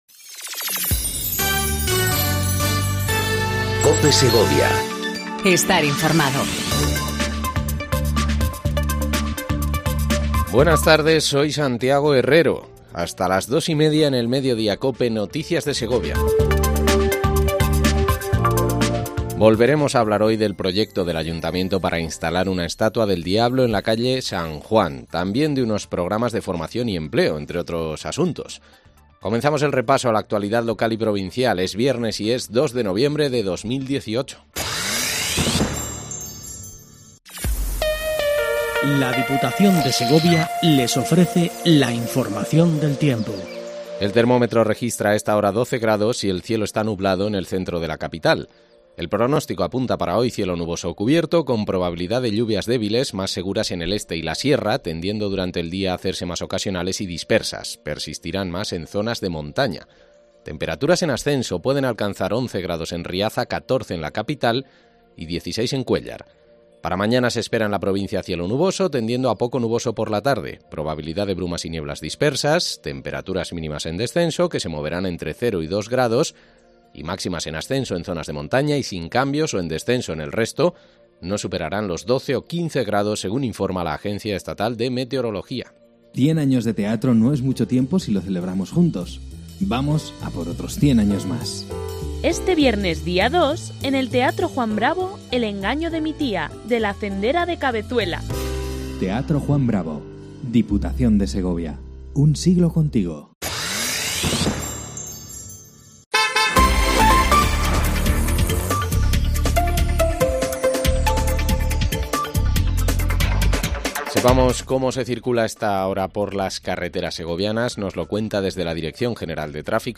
INFORMATIVO MEDIODÍA COPE SEGOVIA 14:20 DEL 02/11/18